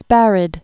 (spărĭd, spâr-)